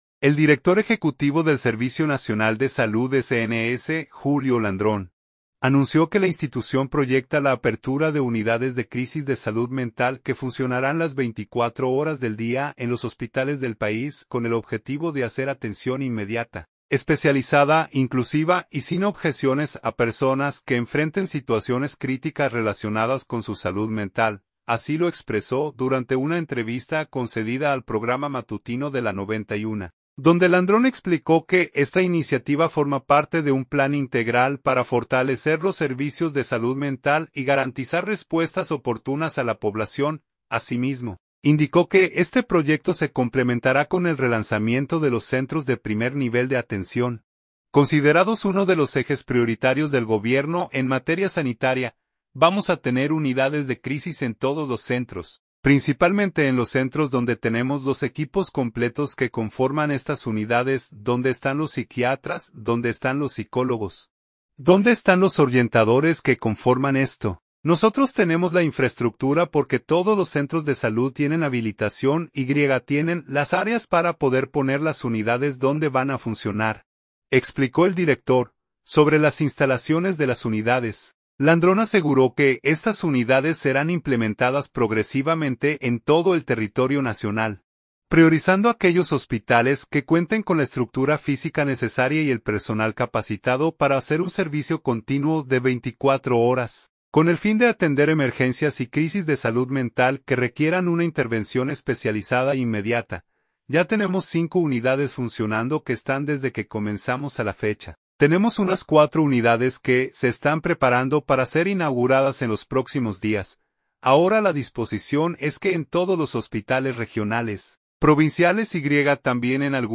Así lo expresó durante una entrevista concedida al programa Matutino de La 91, donde Landrón explicó que esta iniciativa forma parte de un plan integral para fortalecer los servicios de salud mental y garantizar respuestas oportunas a la población.